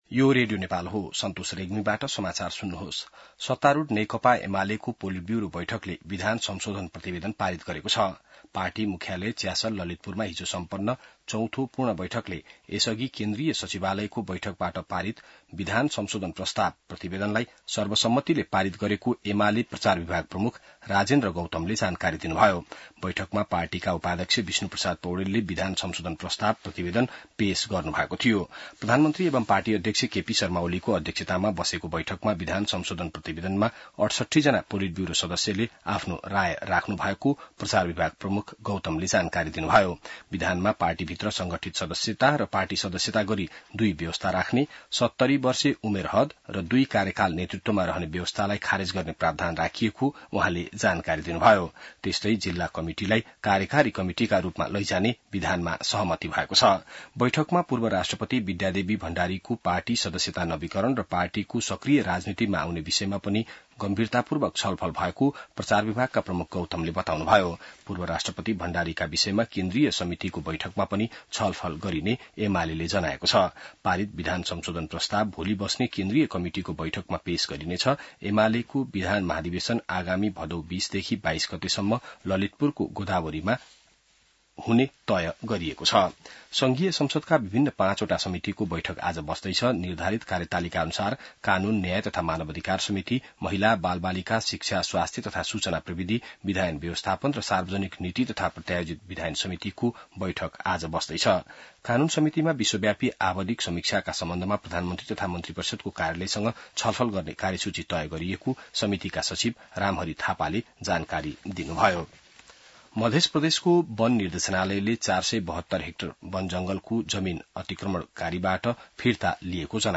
बिहान ६ बजेको नेपाली समाचार : ४ साउन , २०८२